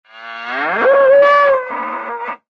Banteng_Suara.ogg